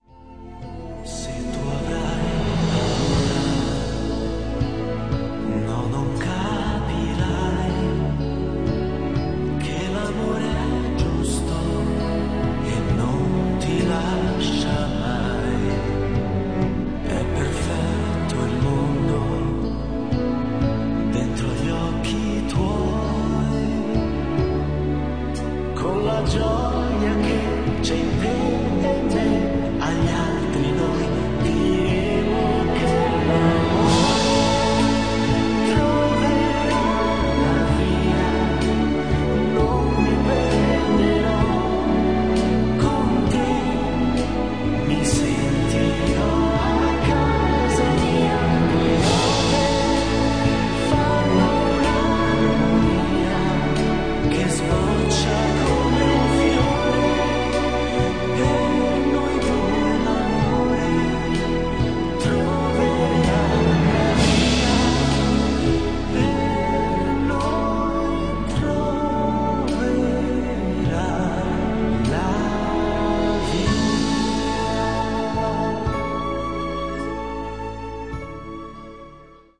film d'animazione
Voce di Kovu, parte cantata